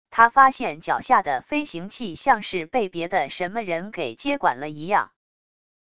他发现脚下的飞行器像是被别的什么人给接管了一样wav音频生成系统WAV Audio Player